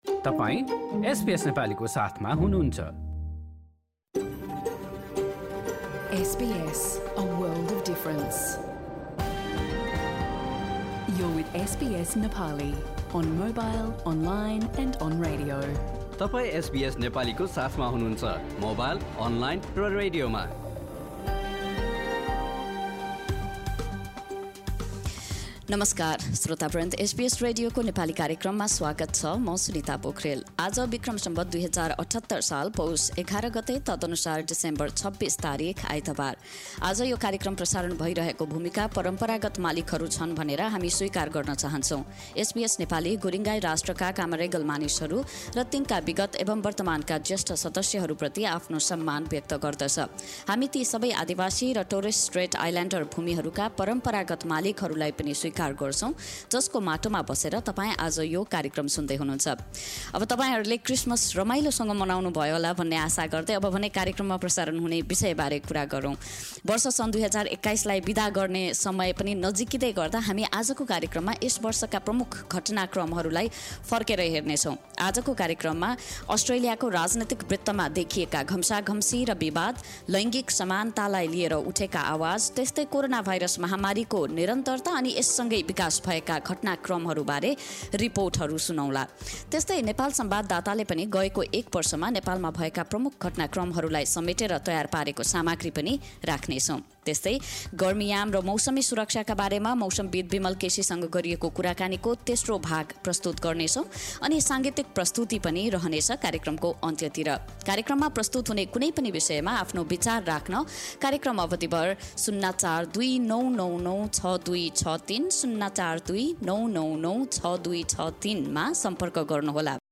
एसबीएस नेपाली रेडियो कार्यक्रम: आइतबार २७ डिसेम्बर २०२१